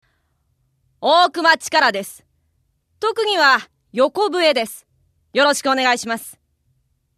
性別 男性
イメージボイス